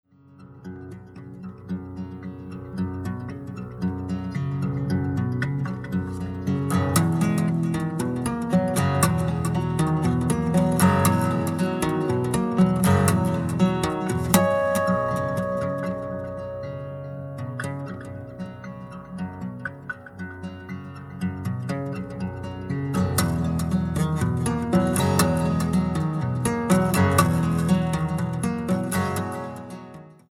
85-90's DANCEHALL